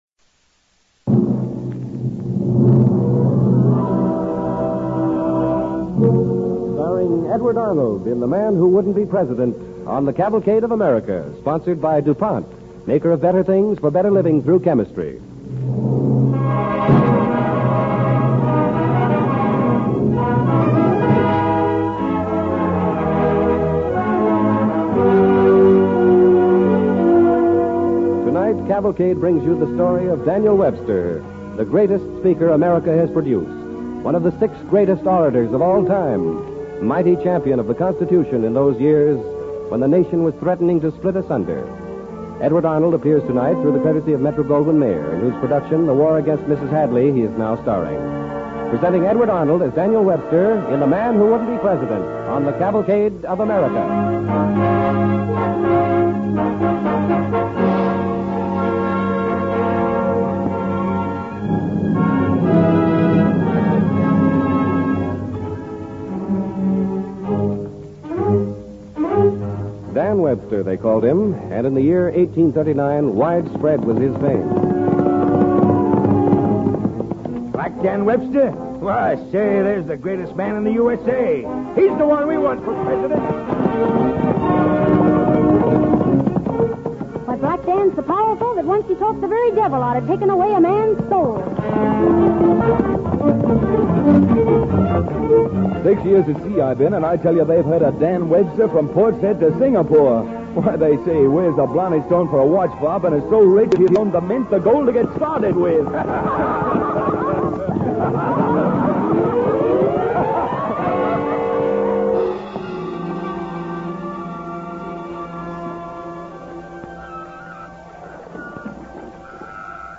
The Man Who Wouldn't Be President, starring Edward Arnold